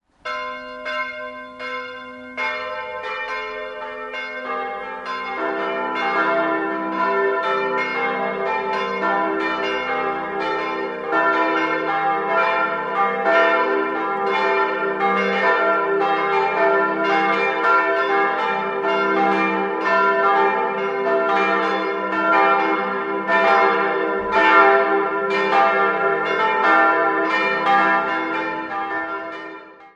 Idealquartett: e'-g'-a'-c'' Die Glocken wurden im Jahr 1950 vermutlich von Czudnochowsky gegossen; nähere Daten liegen nicht vor.